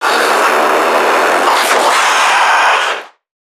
NPC_Creatures_Vocalisations_Infected [11].wav